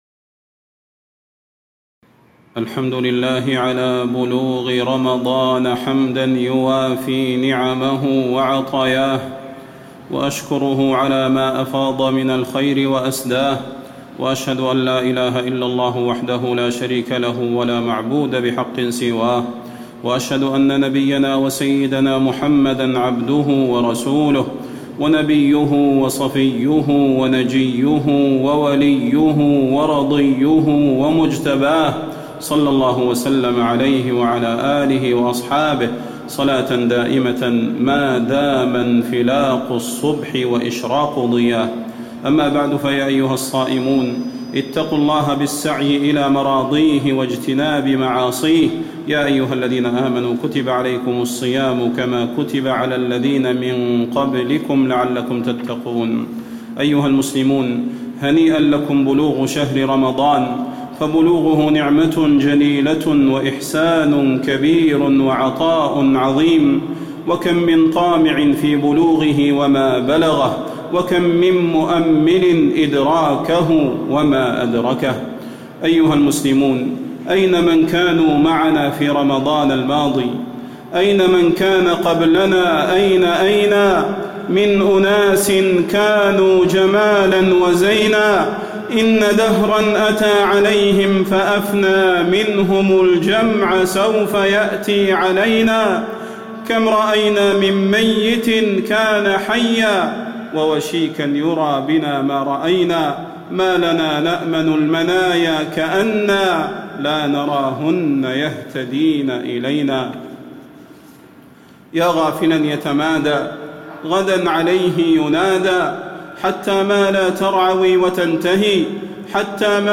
موعظة رمضان
تاريخ النشر ٥ رمضان ١٤٣٧ هـ المكان: المسجد النبوي الشيخ: فضيلة الشيخ د. صلاح بن محمد البدير فضيلة الشيخ د. صلاح بن محمد البدير موعظة رمضان